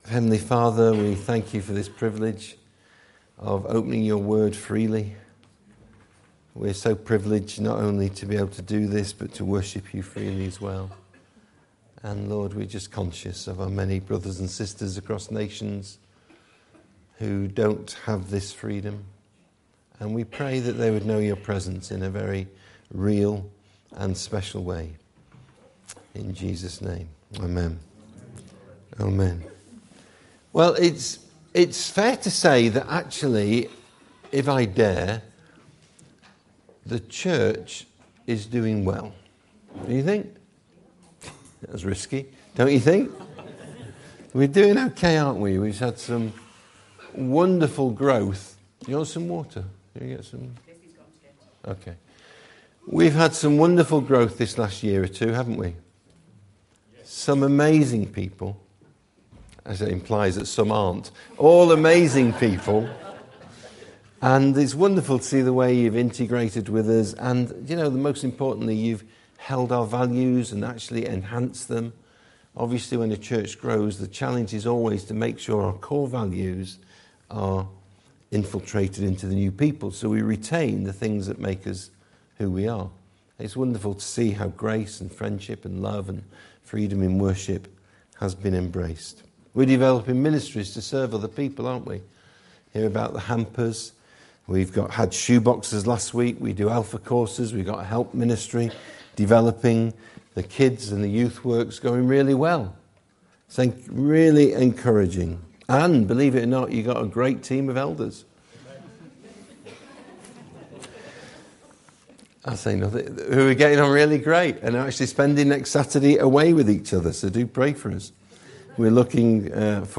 This sermon calls us to live faith filled lives of adventure listening to God speaking and acting upon it.